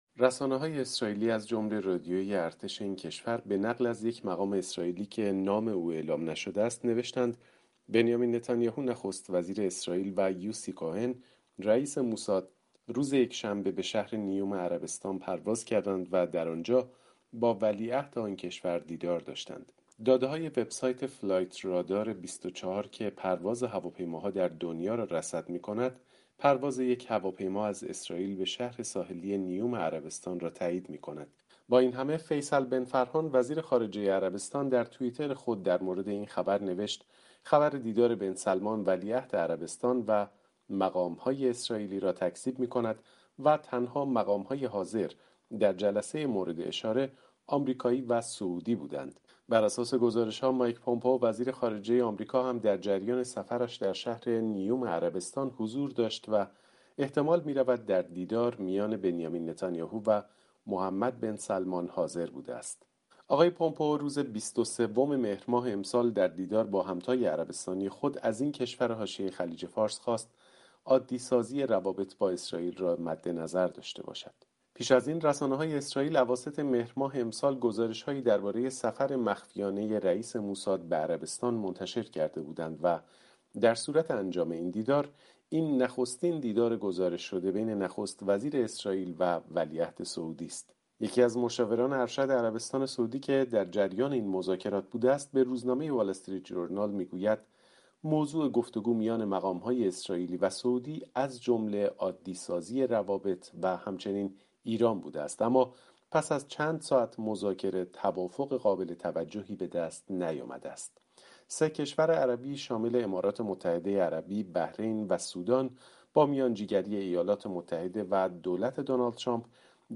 جزئیات بیشتر در گزارشی